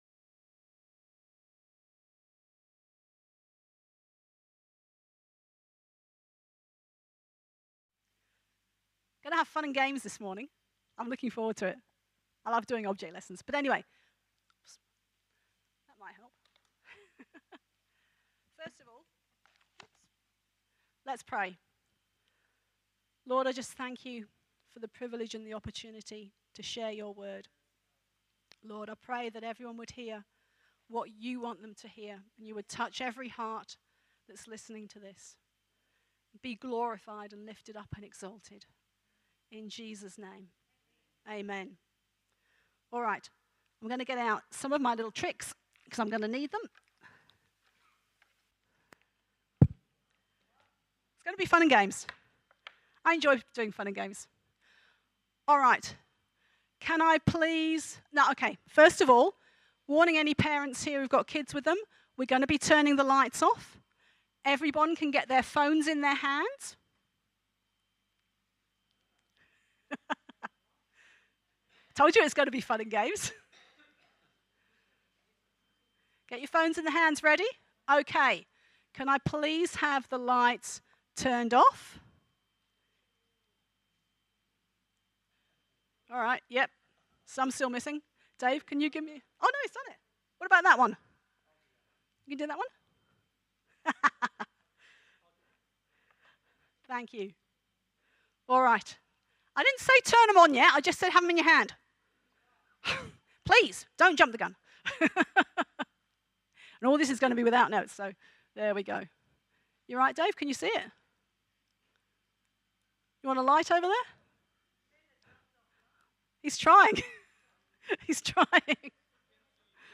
All sermons can now be viewed live or later via the Westpoint City Church YouTube Channel !